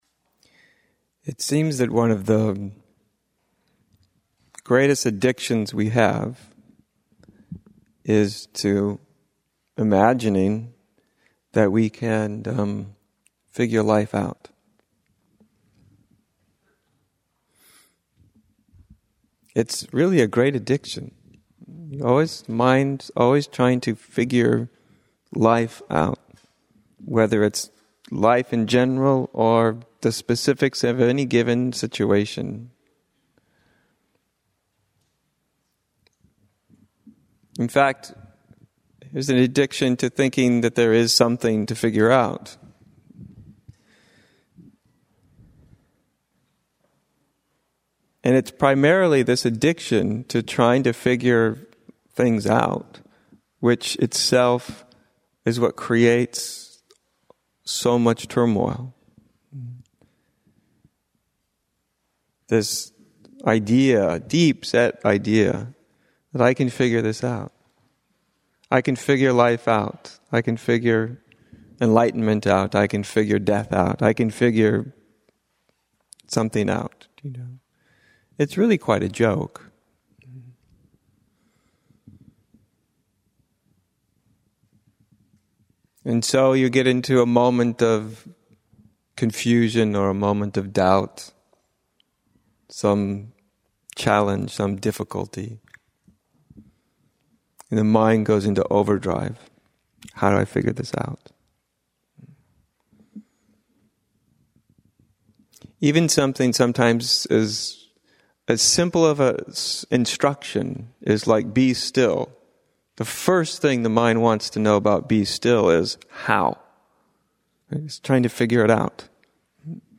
A talk